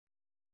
♪ bēsařu